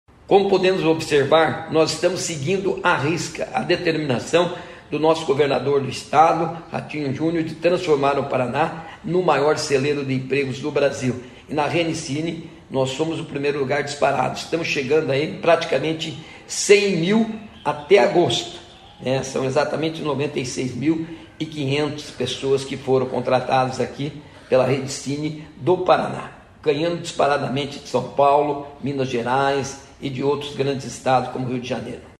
Sonora do secretário do Trabalho, Qualificação e Renda, Mauro Moraes, sobre o recorde de contratações via Agências do Trabalhador no mês de agosto